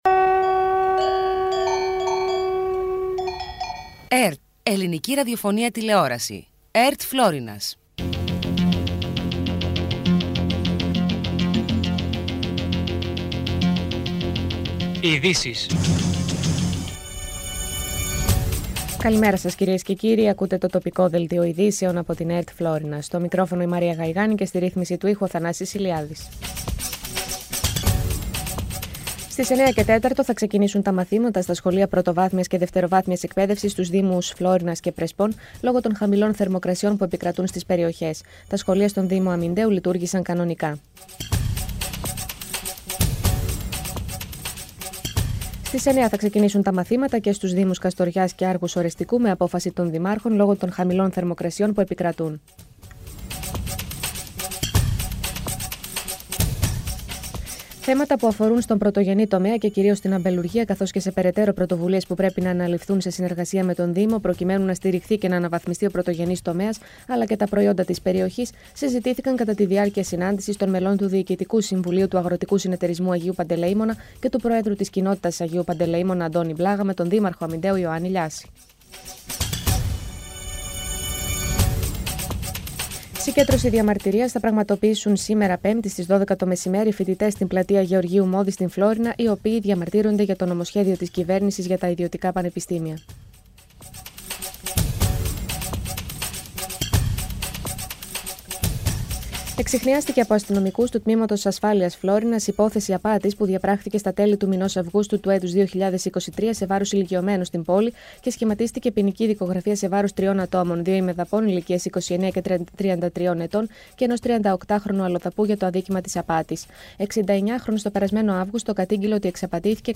Πρωϊνό ενημερωτικό “κους-κους” με διαφορετική ματιά στην ενημέρωση της περιοχής της Φλώρινας και της Δυτικής Μακεδονίας, πάντα με την επικαιρότητα στο πιάτο σας.